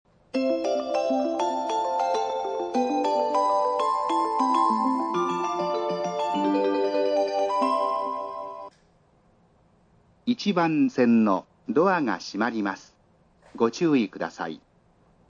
スピーカー：National
音質：A
発車メロディー　(75KB/15秒)   不明 0.9 PCM